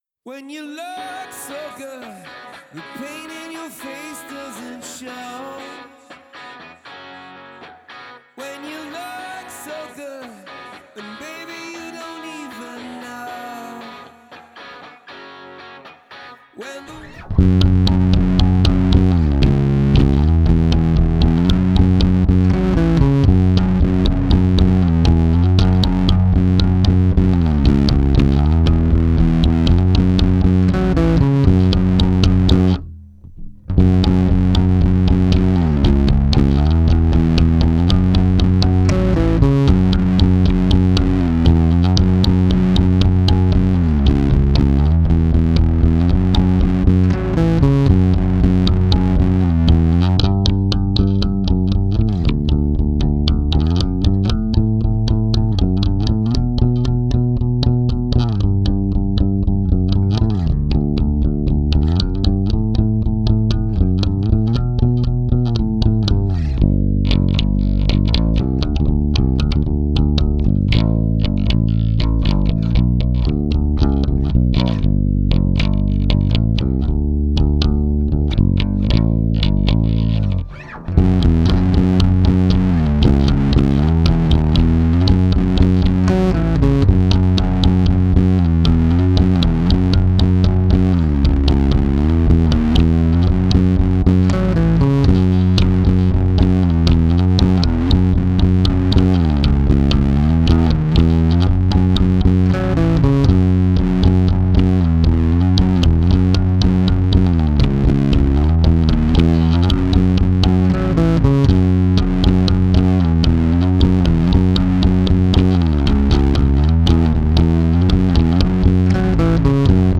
(basse uniquement)